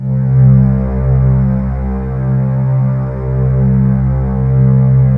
Index of /90_sSampleCDs/USB Soundscan vol.28 - Choir Acoustic & Synth [AKAI] 1CD/Partition D/09-VOCODING
VOCODINGC2-R.wav